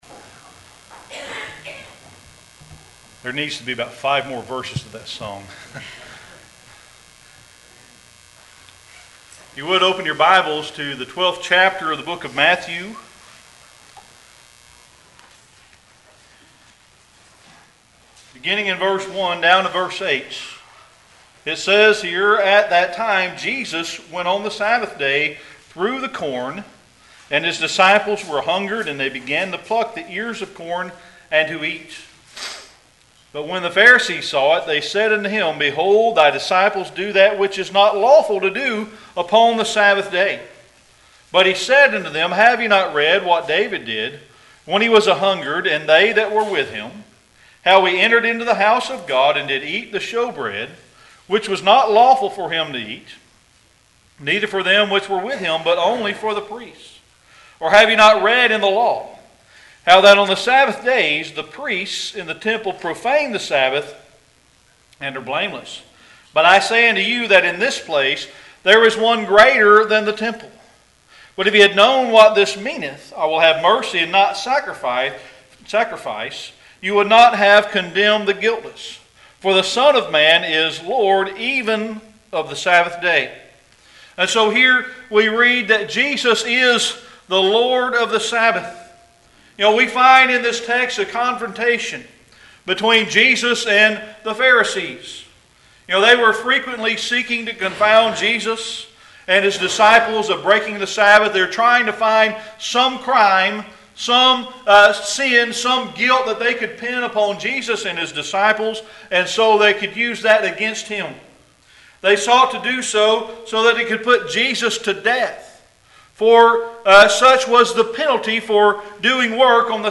Sermon Archives Passage: Matthew 12:1-8 Service Type: Sunday Evening Worship We read that Jesus is the Lord of the Sabbath.